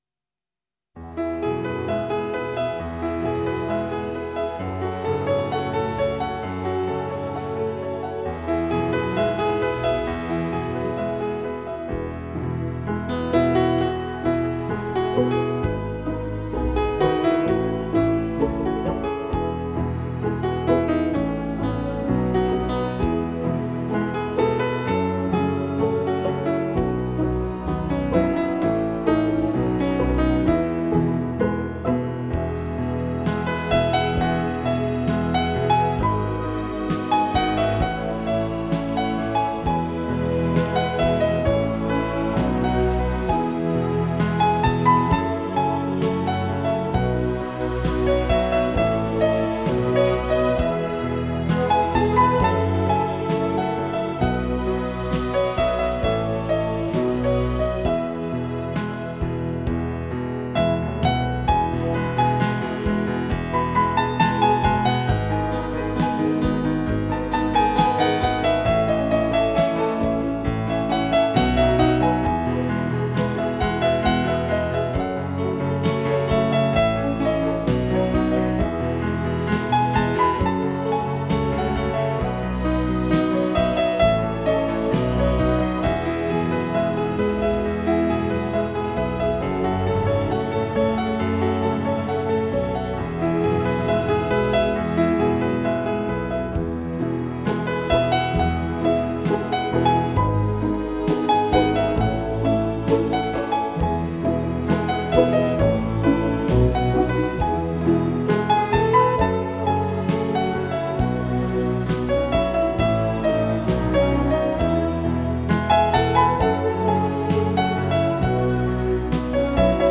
2周前 纯音乐 8